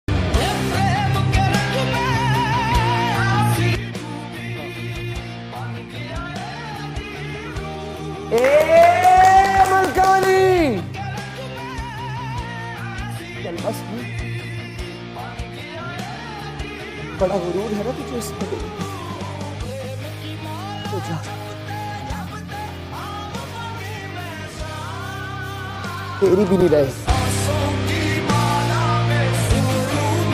Emotional acting Imran Asrahf 🤕 sound effects free download